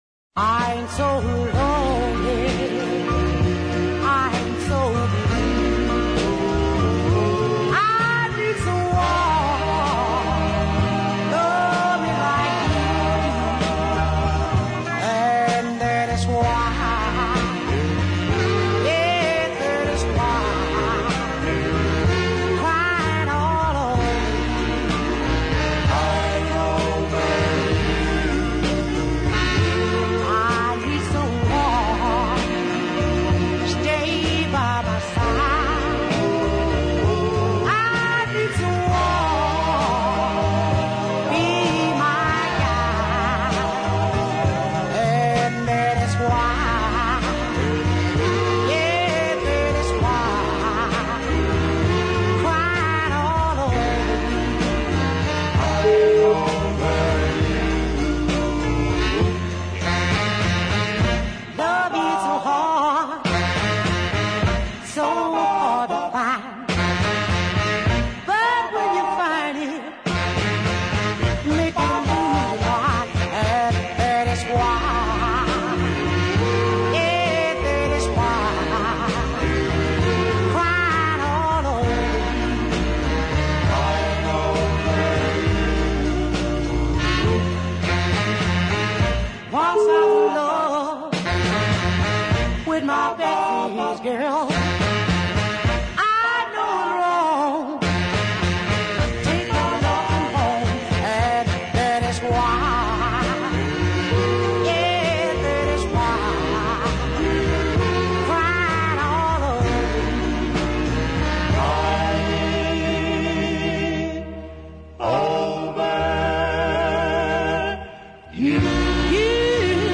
right in the doo wop bag
thanks to the gospel overtones in the harmonies